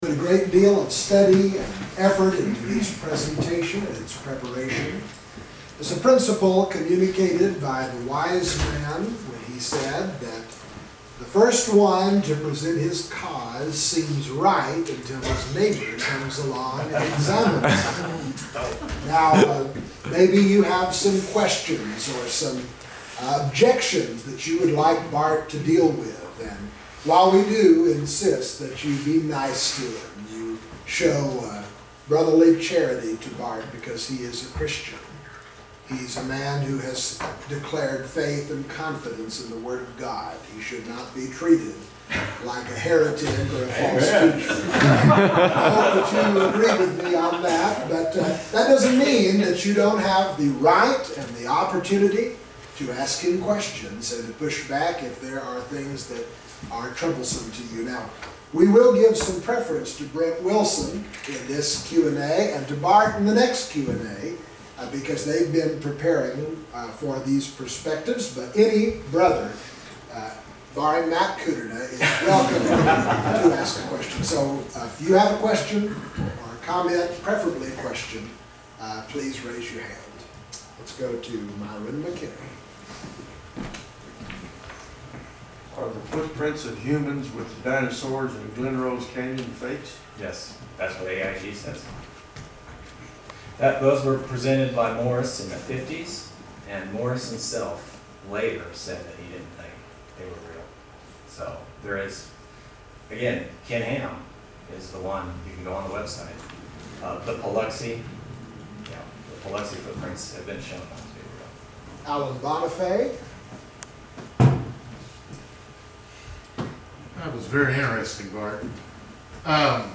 The-Fossil-Record-and-Apparent-Age-QA-MP3-.mp3